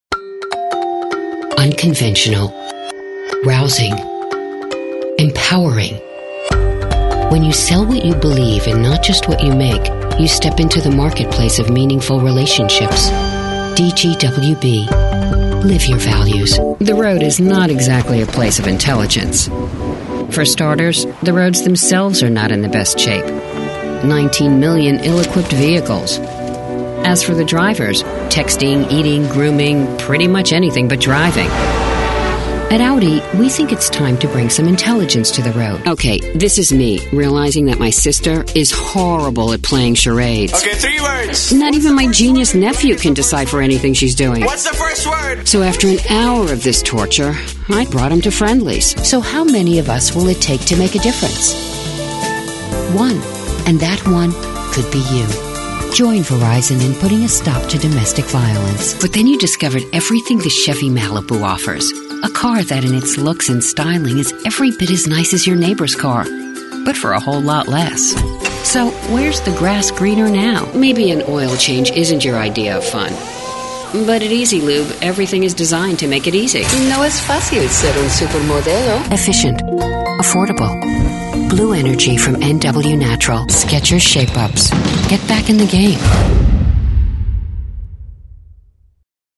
Full-time with professional ISDN equipped studio. Powerful intelligent reads.
Bilingual Female Voiceover Talent w/ISDN.
Sprechprobe: Werbung (Muttersprache):